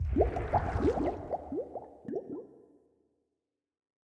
whirlpool_ambient2.ogg